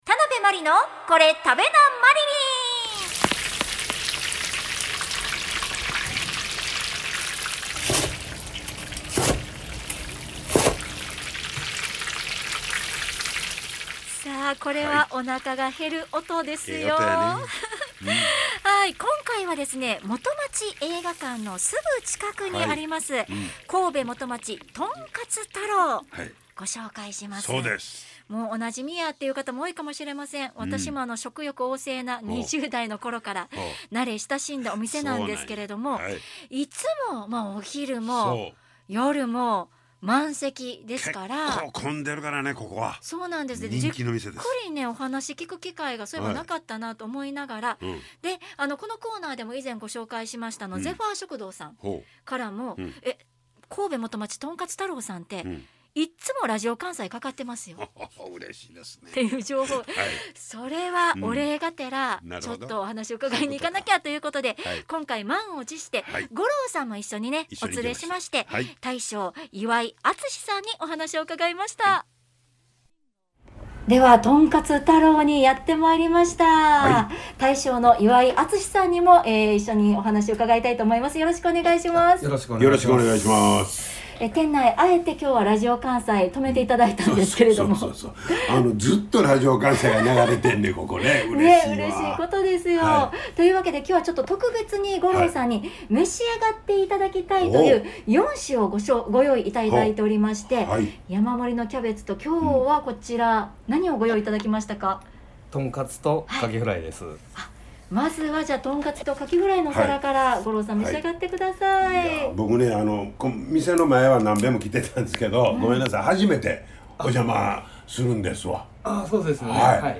【放送音声】